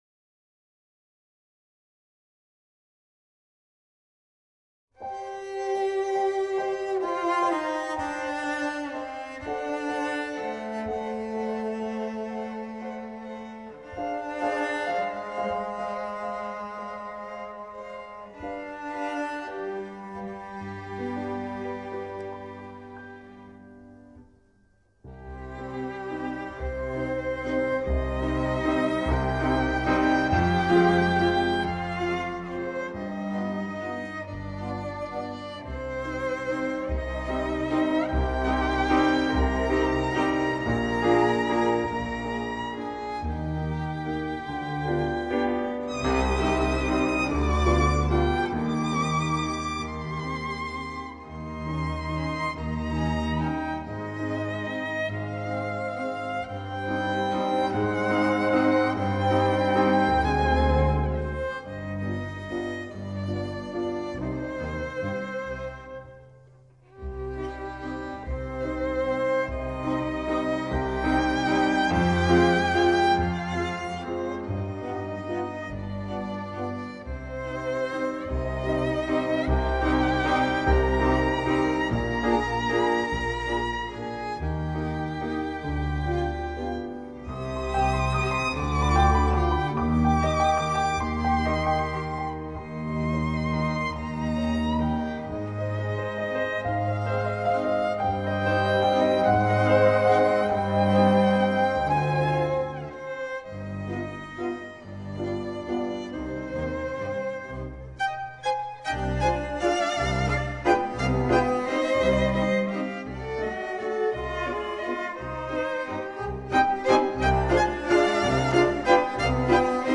Выжившие пассажиры рассказывали, что слышали в ту ночь звуки популярного вальса "Songe d'Automne" и англиканского гимна "Nearer my God to thee".